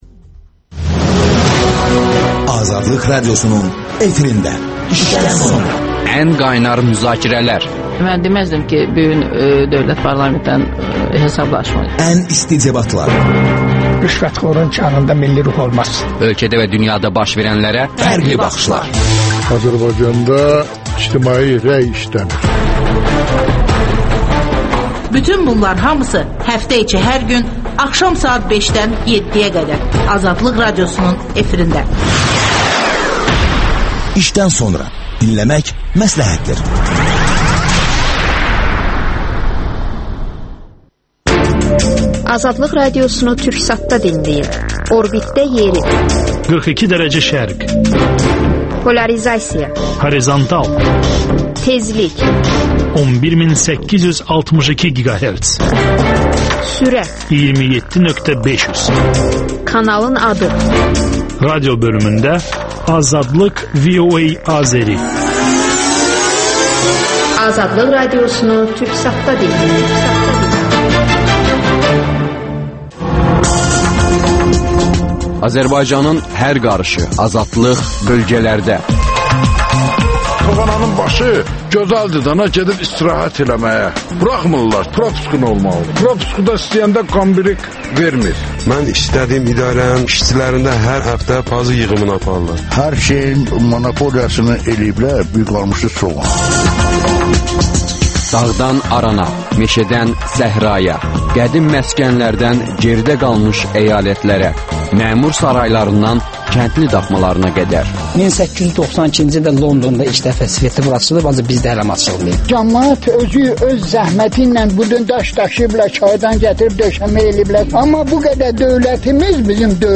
«Bu, lazımdırmı?» sualı ətrafında müzakirə aparırlar.